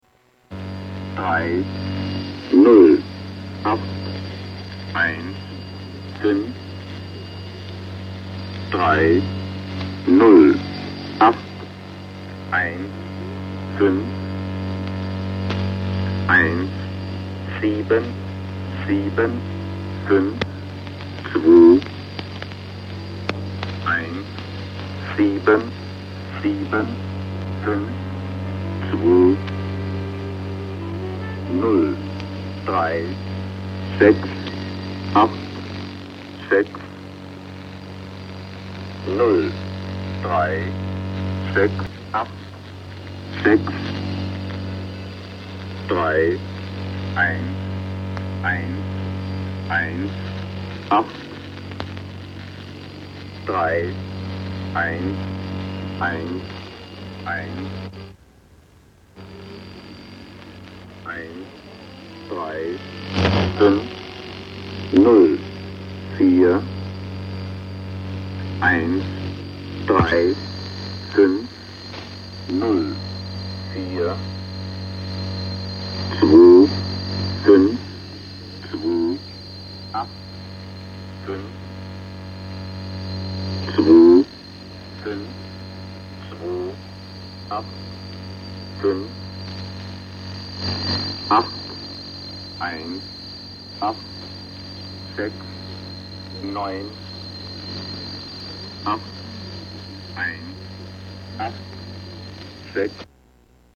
German Man Irdial
Tags: Radio Broadcast Secret Spy Broadcast NATO Number Station